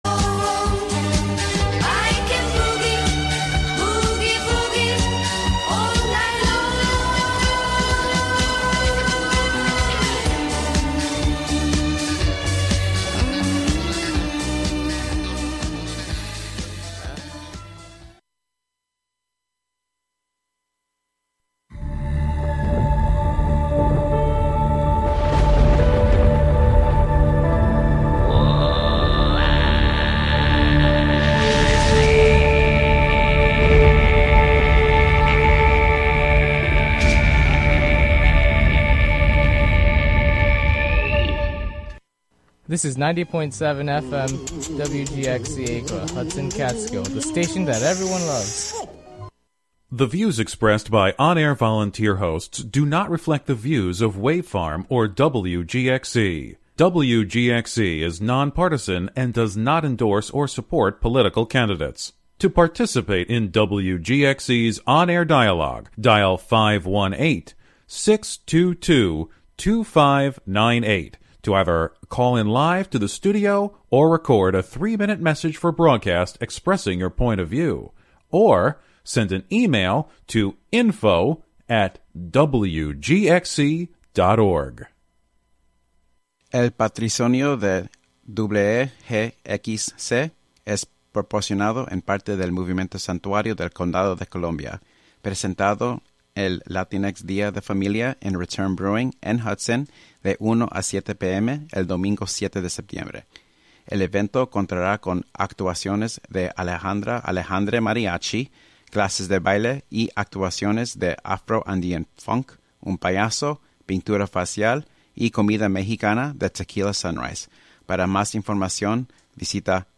Each show, "The Radio Museum" broadcasts a collection of different voices from radio's past. True raconteurs who knew how to tell intimate, personal, engaging stories on the radio.
Plus some music from the era as well as a weekly recipe.